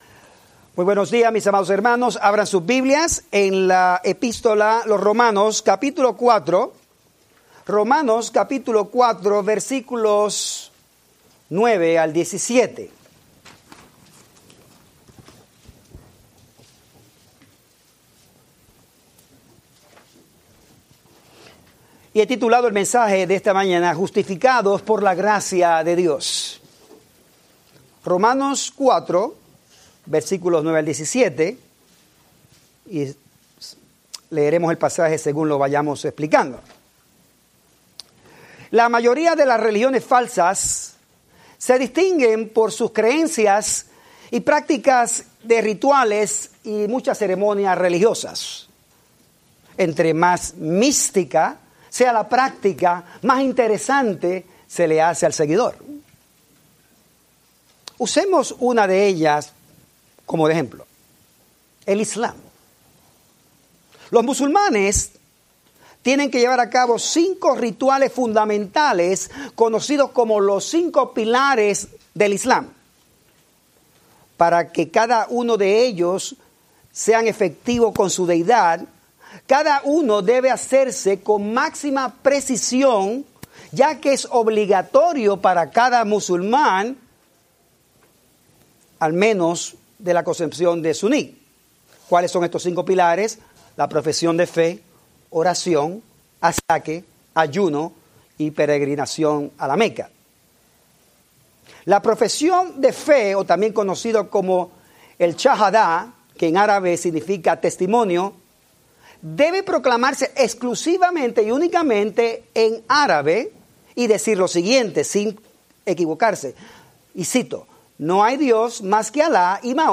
Spanish Bible Study – Así como Abraham fue justificado por la gracia de Dios por medio de la fe, cada creyente en Cristo es declarado justo delante de Dios.